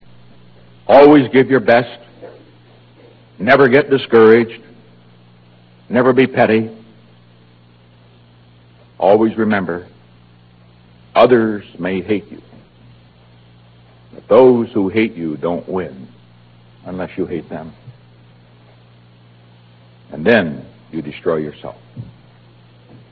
President Nixon's
Resignation Speech